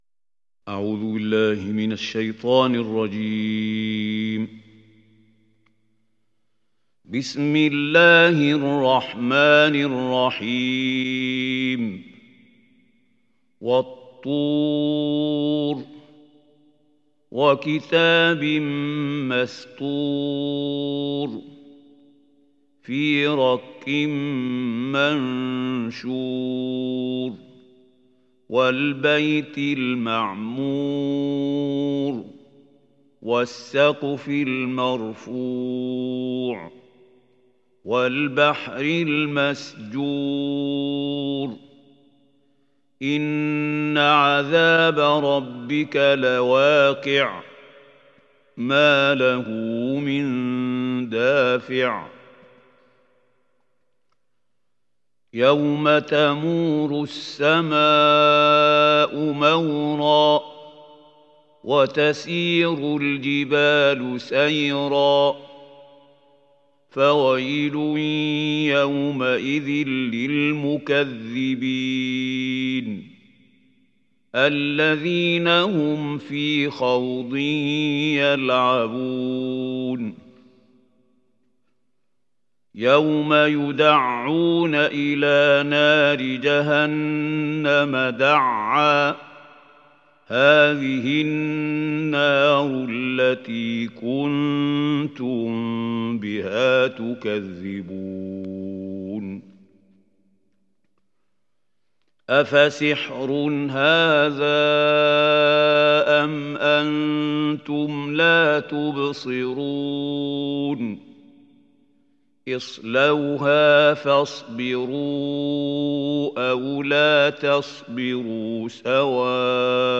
Tur Suresi İndir mp3 Mahmoud Khalil Al Hussary Riwayat Hafs an Asim, Kurani indirin ve mp3 tam doğrudan bağlantılar dinle